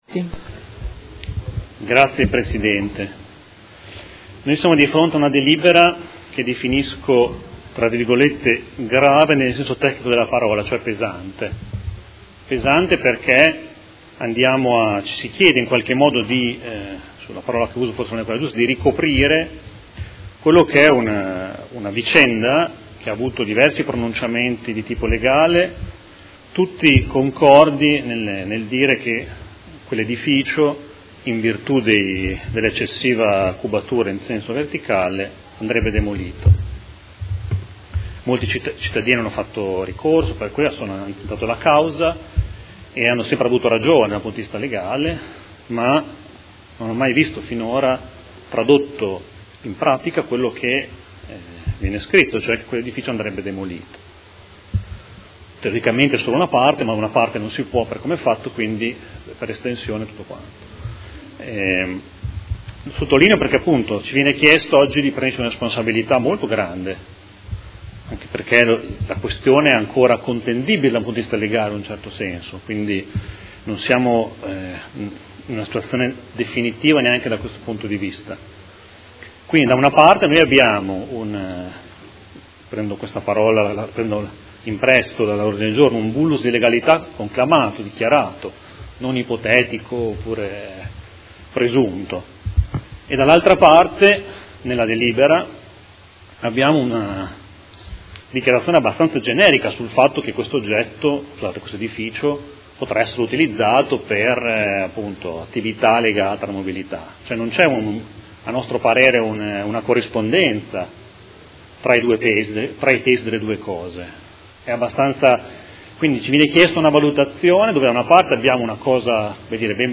Seduta del 20/12/2018. Dibattito su proposta di deliberazione: Dichiarazione di prevalente interesse pubblico ai fini del mantenimento dell'immobile sito in Modena, viale Trento Trieste, catastalmente identificato presso il Catasto Urbano di Modena al foglio 144, mappale 141 subalterni 10,13,18, 19, 21, 23, 25, 36, 27, 28, 29 comprese aree di sedime e aree pertinenziali, ex Ditta B.A. Service S.r.l. ai sensi del comma 5 art. 13 Legge Regionale 23/2004, e mozione